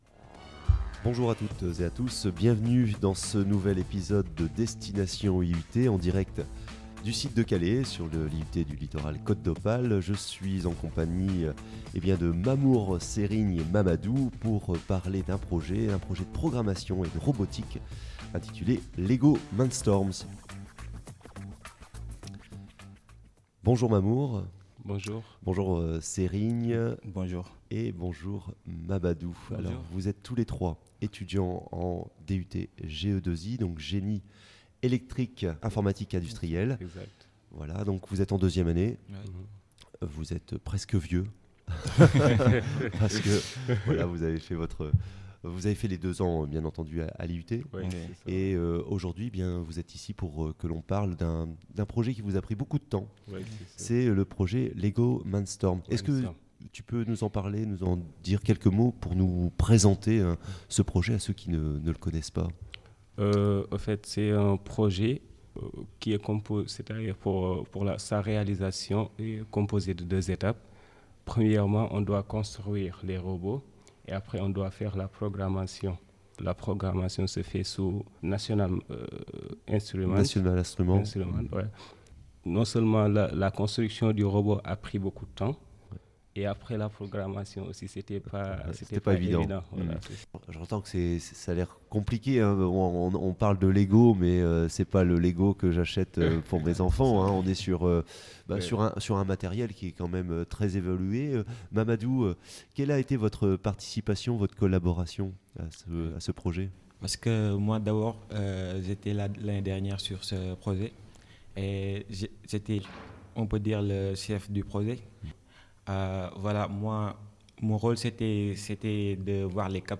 Genre : Interview.